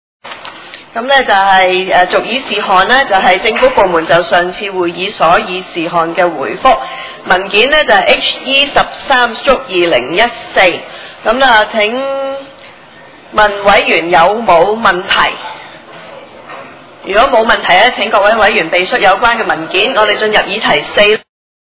委员会会议的录音记录
地点: 沙田区议会会议室